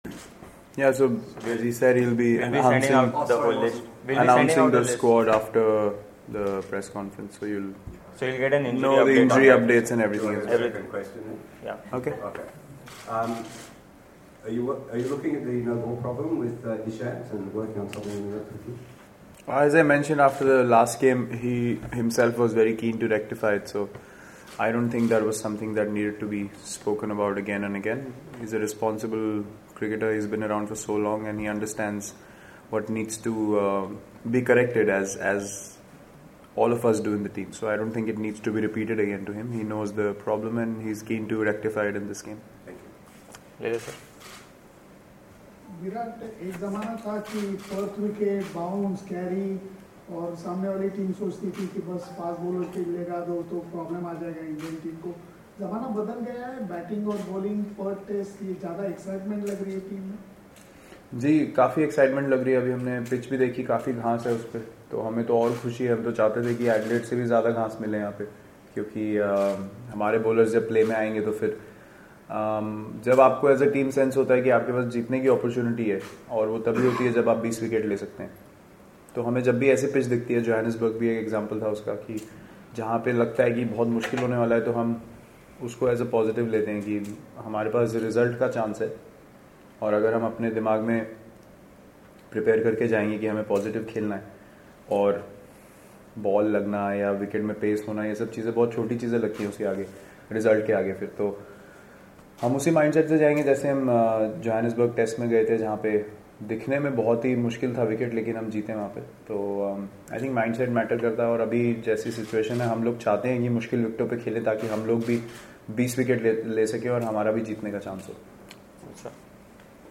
Virat Kohli, Captain, Indian Cricket Team, speaks with the media on Thursday, December 13 in Perth on the eve of the 2nd Test against Australia.